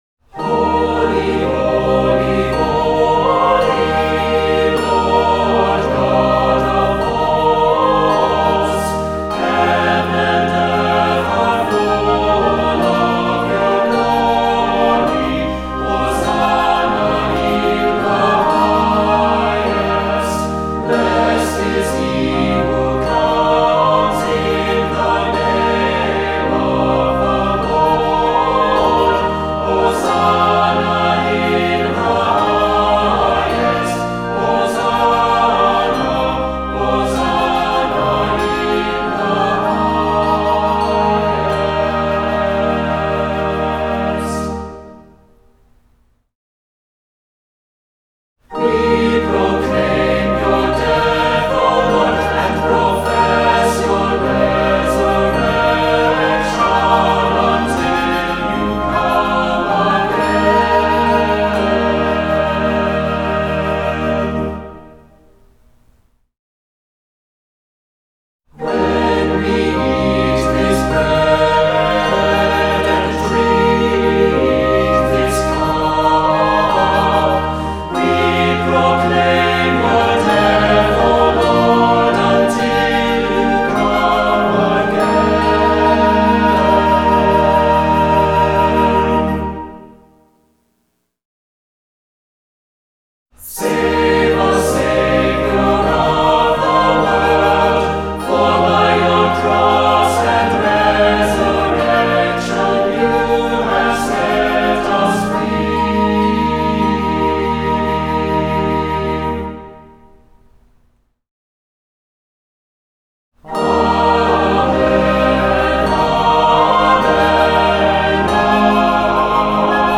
Voicing: Cantor,SATB,Assembly